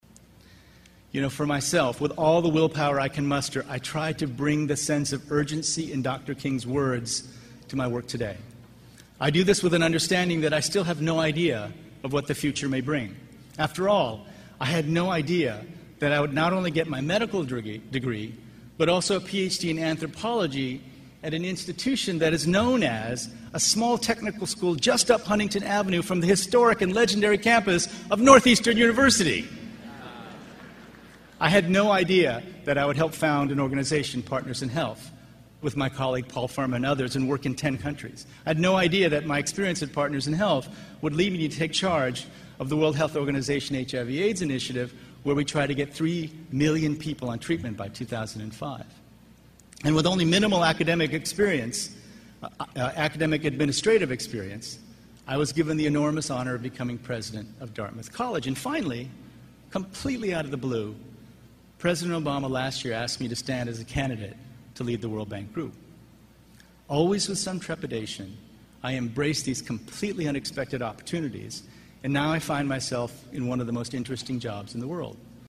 公众人物毕业演讲 第71期:金墉美国东北大学(10) 听力文件下载—在线英语听力室